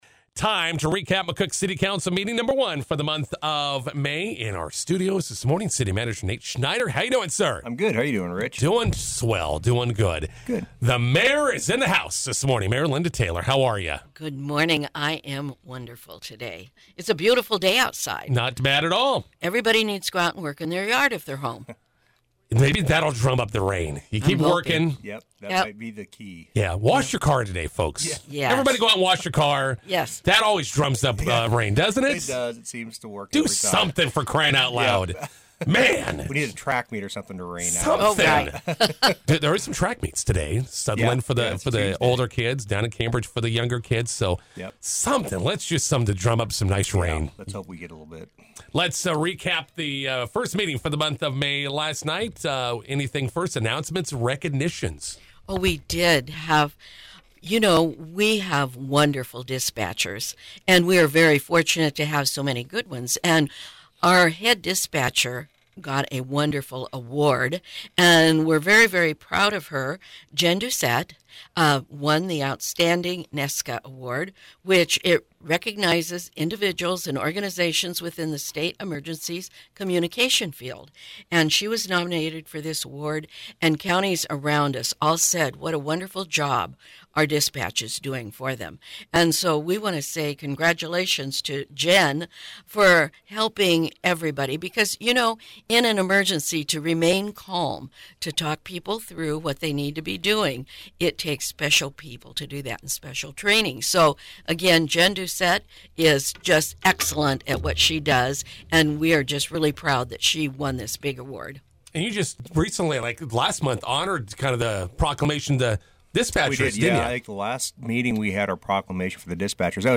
INTERVIEW: McCook City Council meeting recap with City Manager Nate Schneider and Mayor Linda Taylor.